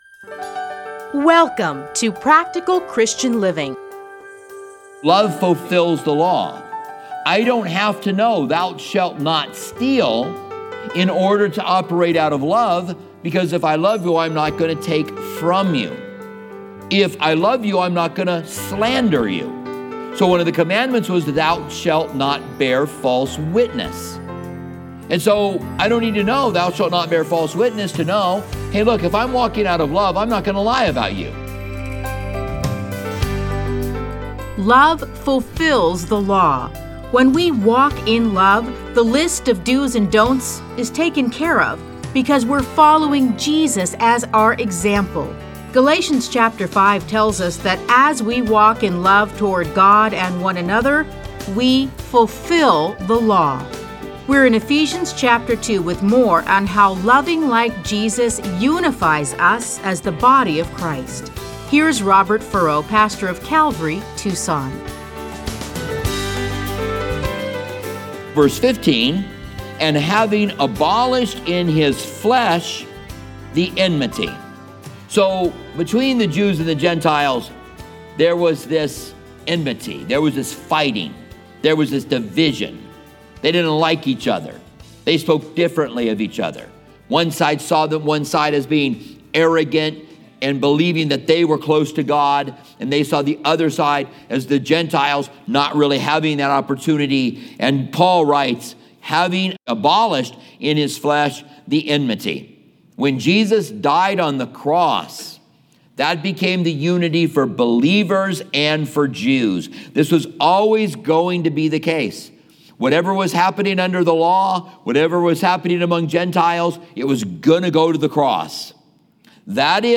Listen to a teaching from Ephesians 2:14-22.